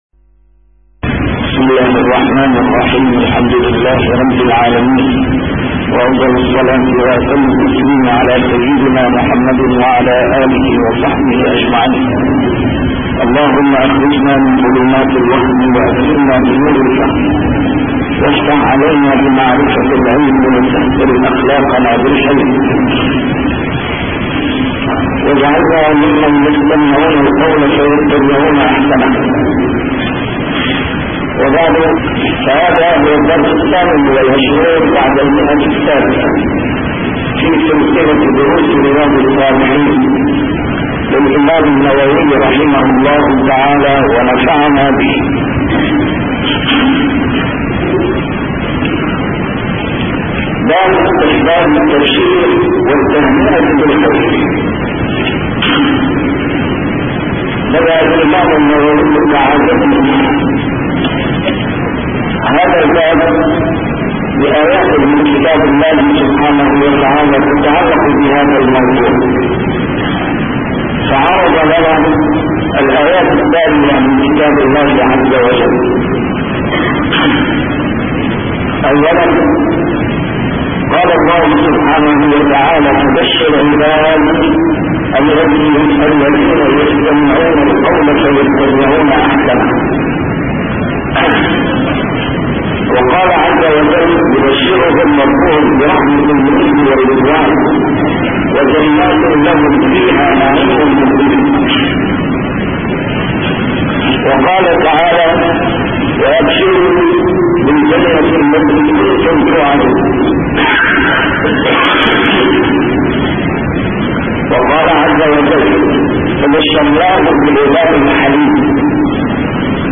A MARTYR SCHOLAR: IMAM MUHAMMAD SAEED RAMADAN AL-BOUTI - الدروس العلمية - شرح كتاب رياض الصالحين - 628- شرح رياض الصالحين: استحباب التبشير والتهنئة بالخير